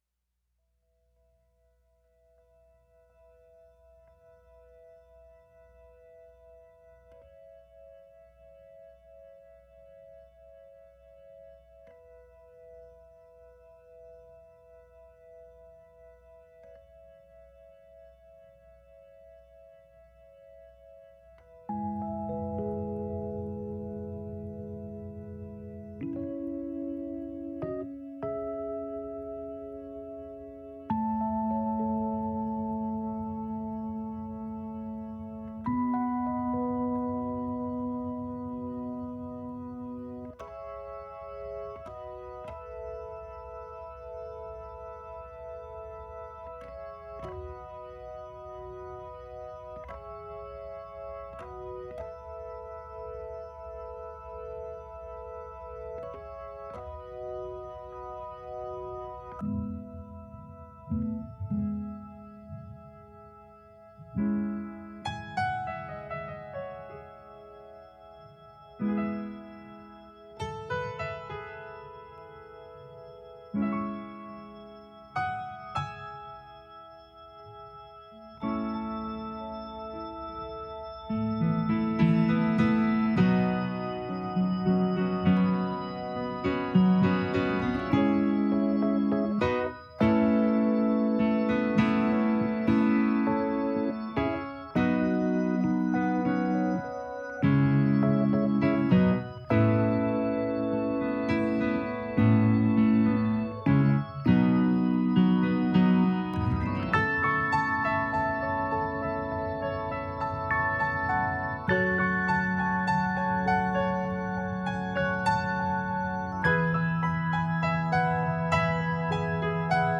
Bergmann Upright Acoustic Piano (Samples above)
• Universal Audio Rhodes Suitcase with Various Speaker Options and FX Pedals (Samples above)
• Universal Audio: Hammond Organ & Leslie Rotary Speaker (Requires organ midi controller with Leslie Speed Pedal) (Samples above)